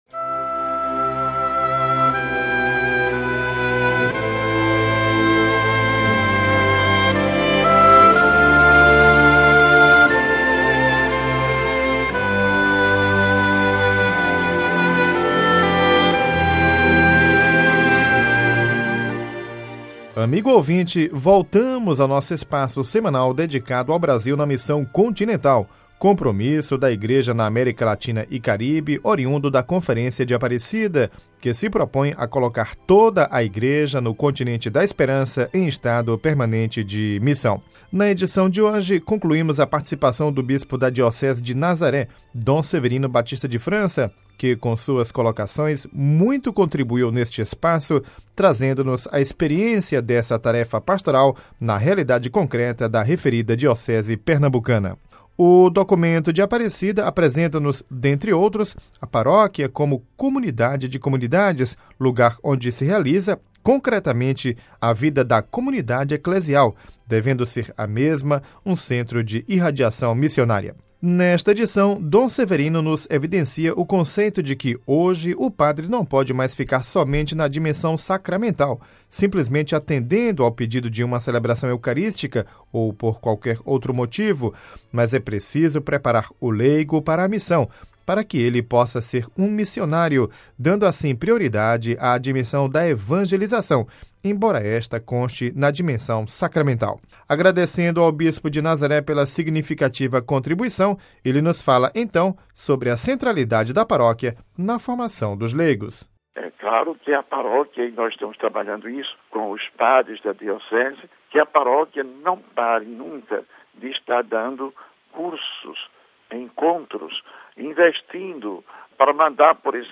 Na edição de hoje concluímos a participação do bispo da Diocese de Nazaré, Dom Severino Batista de França, que com suas colocações muito contribuiu neste espaço trazendo-nos a experiência dessa tarefa pastoral na realidade concreta da referida diocese pernambucana.